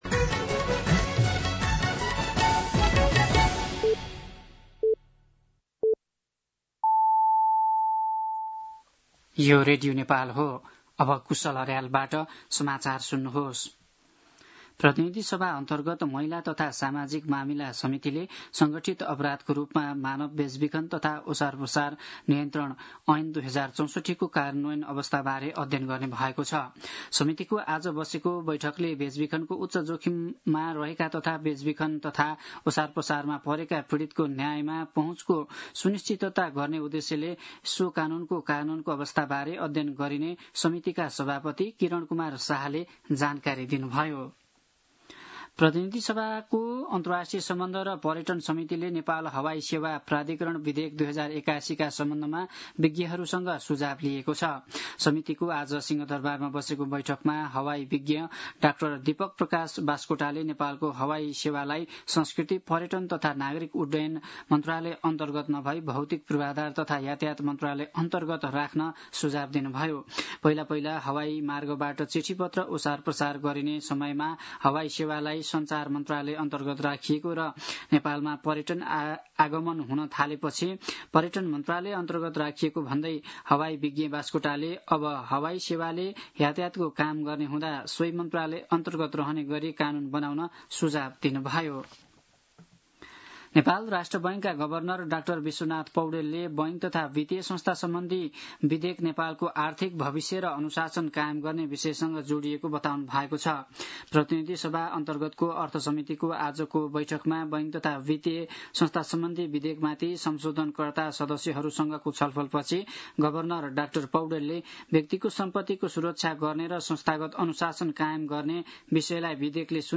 दिउँसो ४ बजेको नेपाली समाचार : १९ असार , २०८२
4-pm-News-03-19.mp3